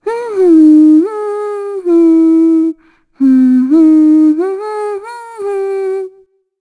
Shea-Vox_Hum.wav